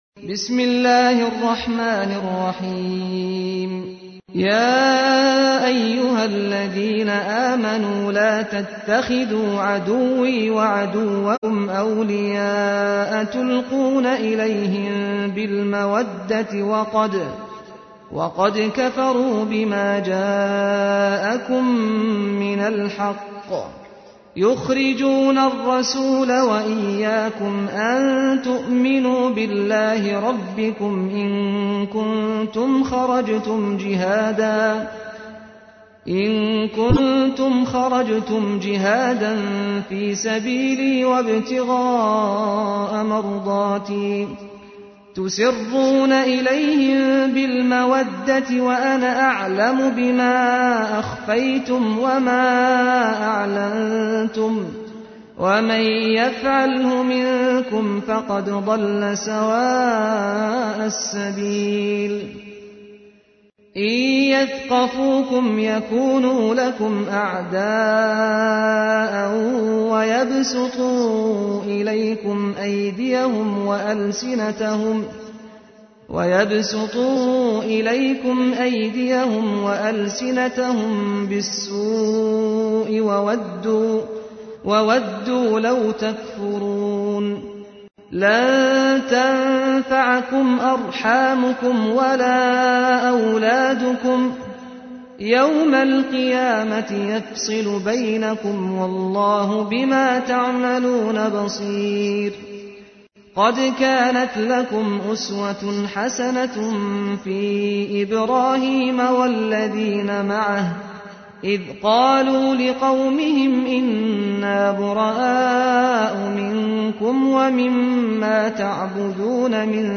تحميل : 60. سورة الممتحنة / القارئ سعد الغامدي / القرآن الكريم / موقع يا حسين